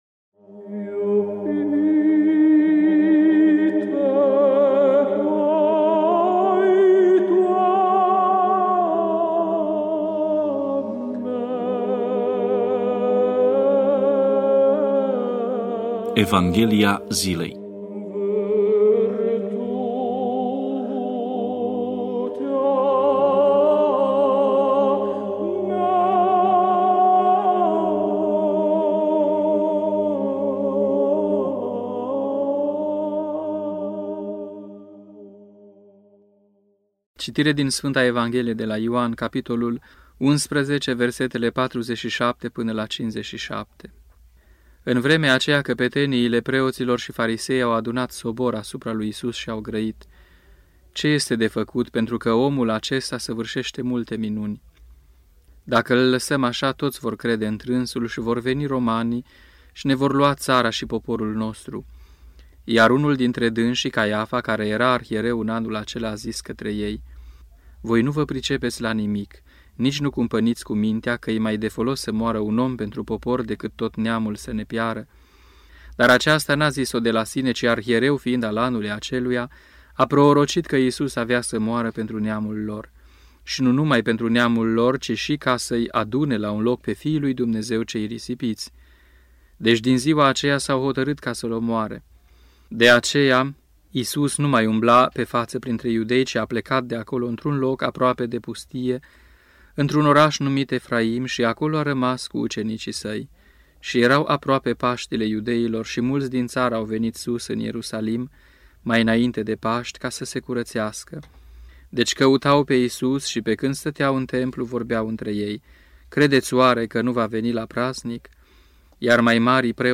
Meditație la Evanghelia zilei Unul pentru toți (Luni, săptămâna a 6-a după Paști) Play Episode Pause Episode Mute/Unmute Episode Rewind 10 Seconds 1x Fast Forward 30 seconds 00:00 / 9:51 Subscribe Share RSS Feed Share Link Embed